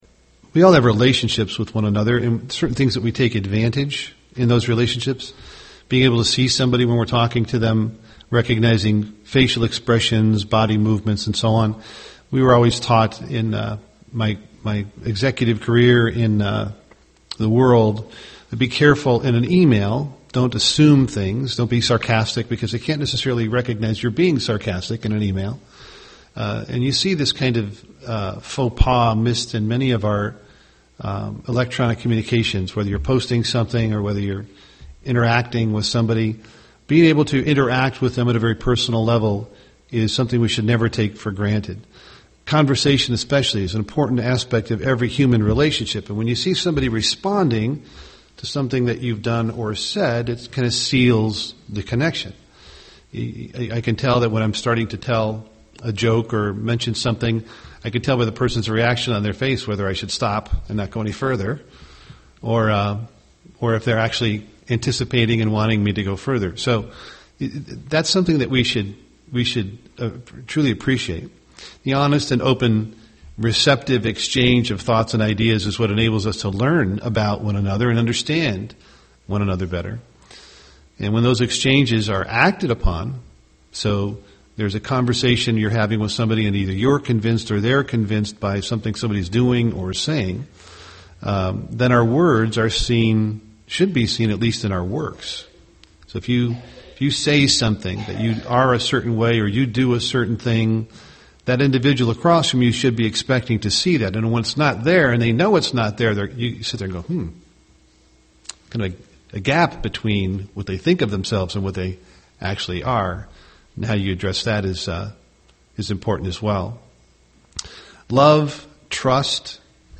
UCG Sermon pleasing God Studying the bible?